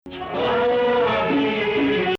Maqam Rast
Performer: Marie Jubran
Nahawand 5
Rast Sihtu 23 Nahawand.mp3